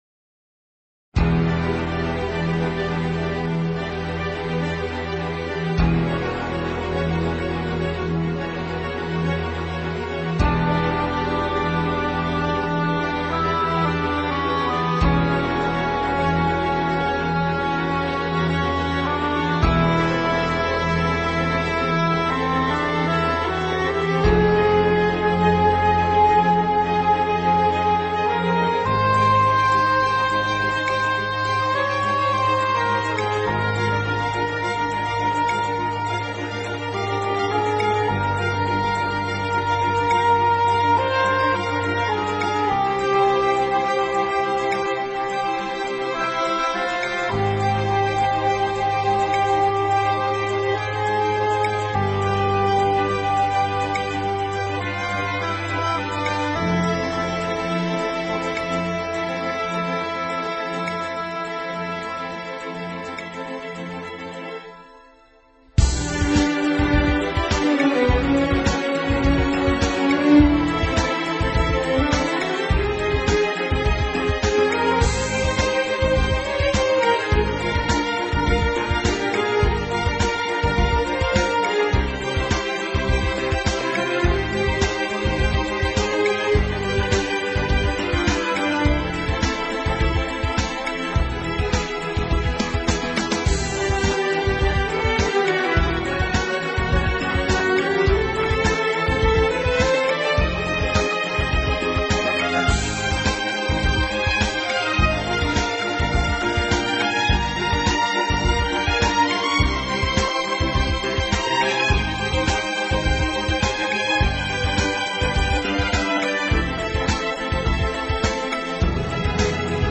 音乐风格： 其他|古典|Neo Classical，室内乐